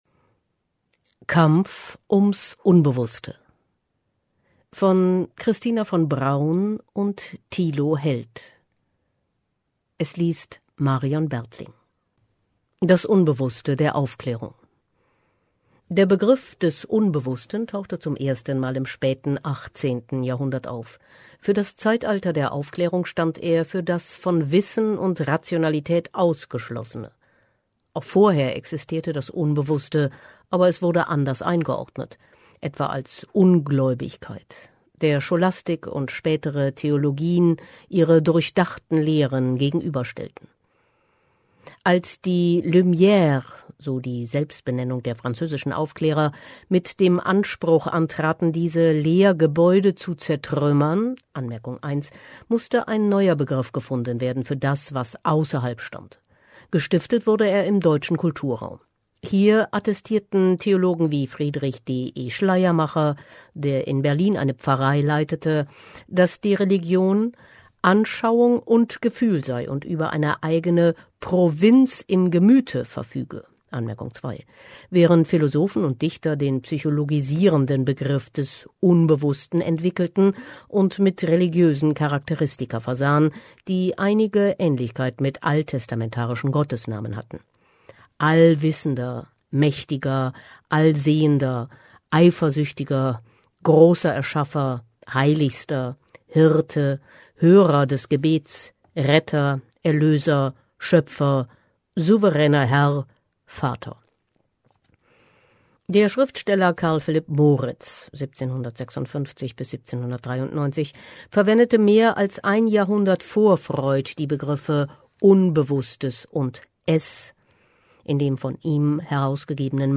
liest diese philosophische Betrachtung gesellschaftlicher Entwicklungen: